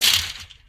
sounds / material / human / step